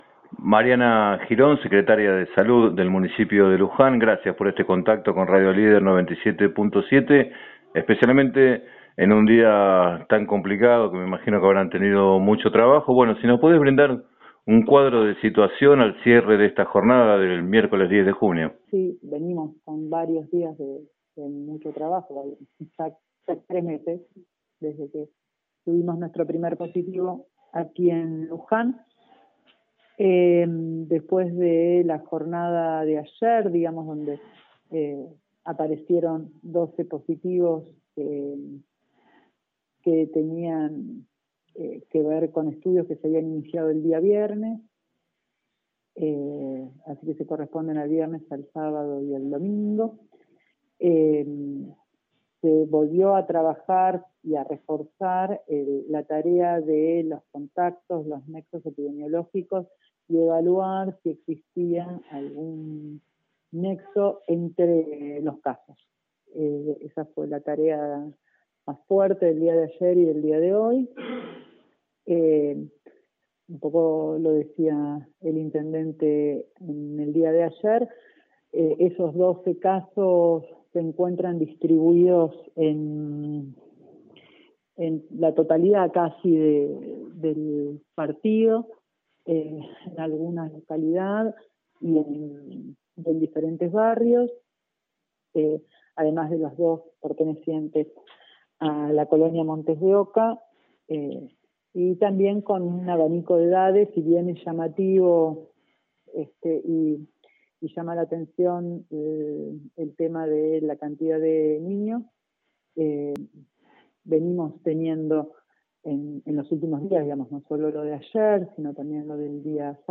Así lo explicó a Radio Líder 97.7 la secretaria de Salud, Mariana Girón.